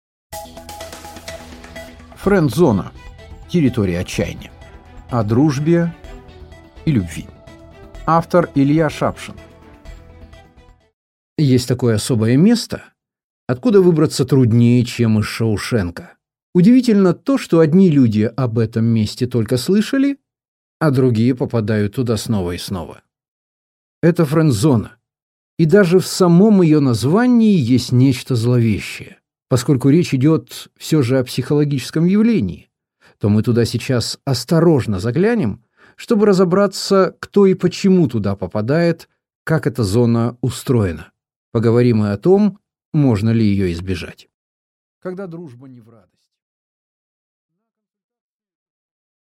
Аудиокнига Френдзона – территория отчаяния | Библиотека аудиокниг